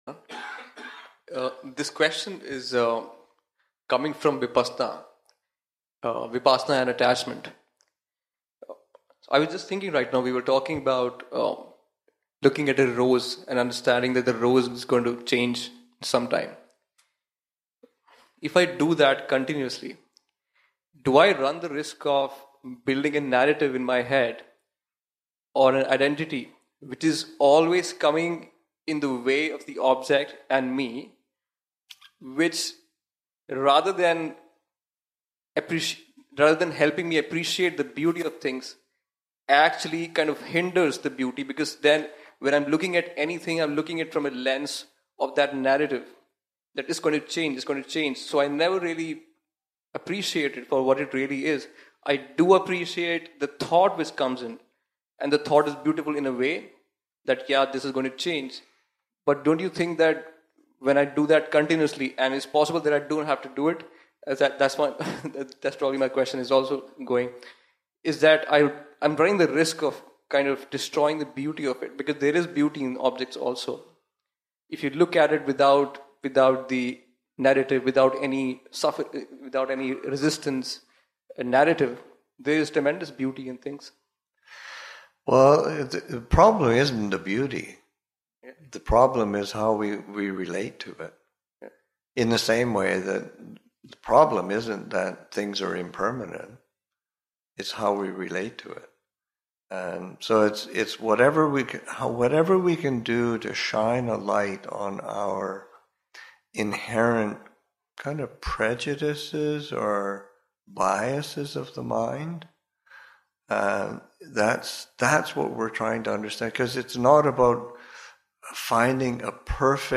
Interreligious Retreat-Seminar on Dhamma and Non-duality, Session 1 – Nov. 24, 2023